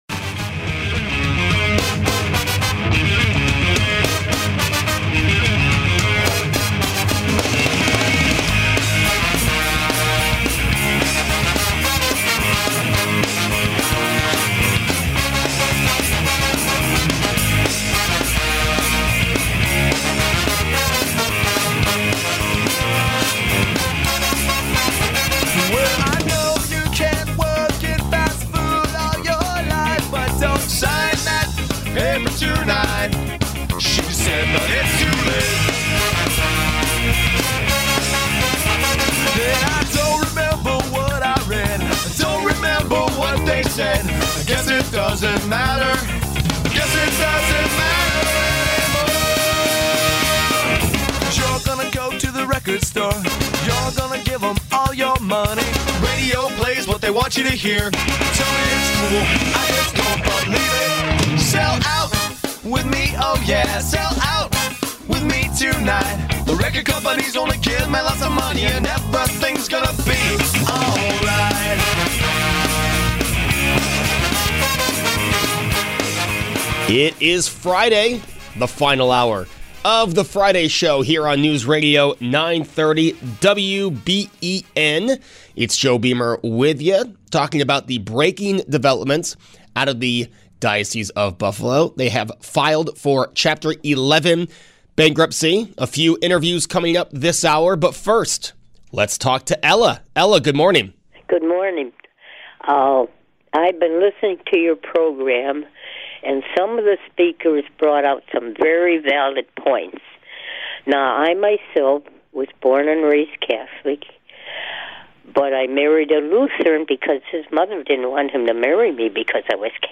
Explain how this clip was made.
Taking your calls on the Breaking News that the Diocese of Buffalo has filed for Chapter 11 Bankruptcy.